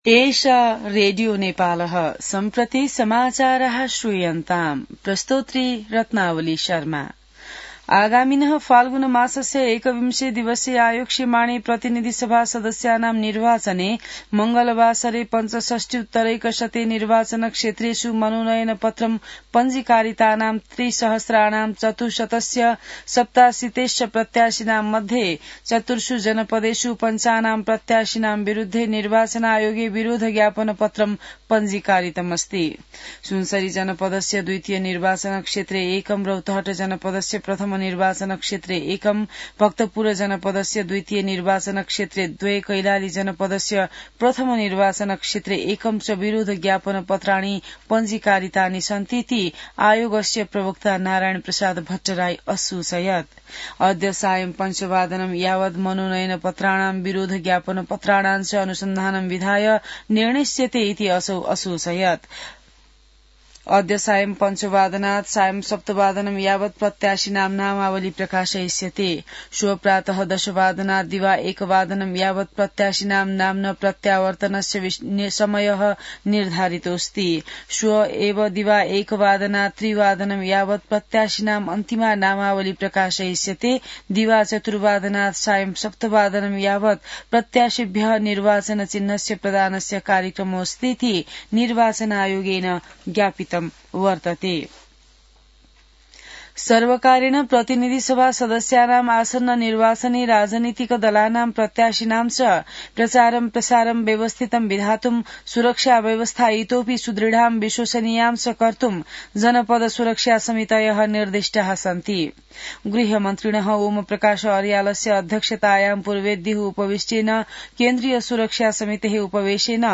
संस्कृत समाचार : ८ माघ , २०८२